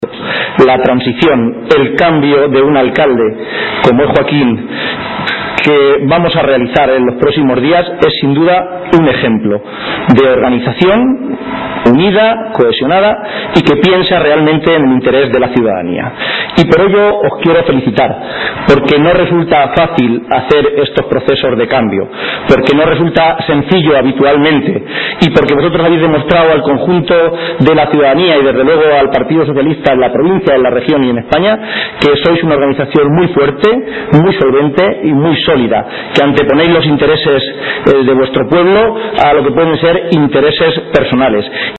Unas palabras pronunciadas por García-Page en Puertollano, municipio al que ha acudido para participar en un acto informativo a la militancia organizado para dar detalles del proceso de cambio y renovación que se producirá en apenas dos semanas con el relevo en la Alcaldía que Joaquín Hermoso Murillo dará a Mayte Fernández.